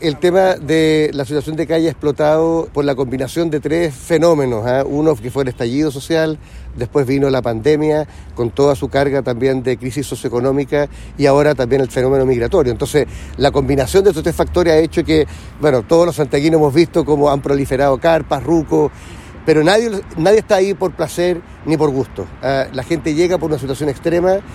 El gobernador Orrego indicó que se debe realizar un plan integral de reinserción social para ir en ayuda de las personas sin techo, cuya cantidad ha ido al alza por diversos factores, como la inmigración, el estallido social y la pandemia.